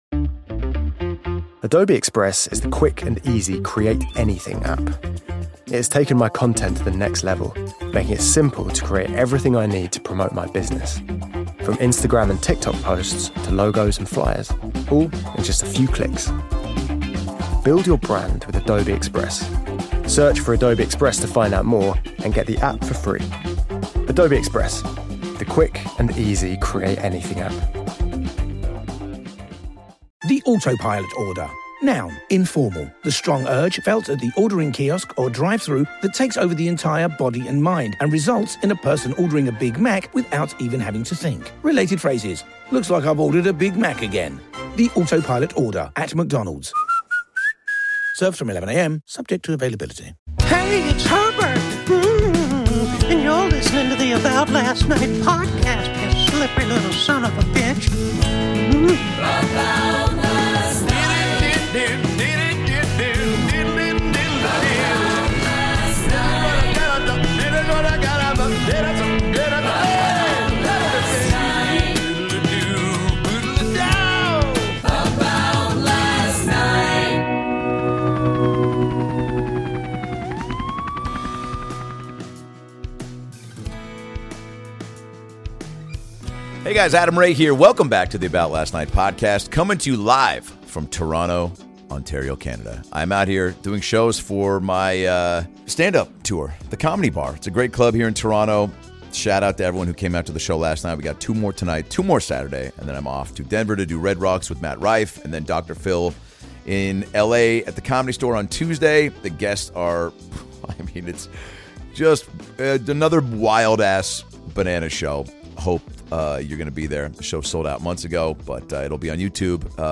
Dr, Phil is coming from Meridian Hall in Toronto and is sitting down with Brian Quinn and Steph Tolev for some deep-ish conversations.
Adam Ray as Dr. Phil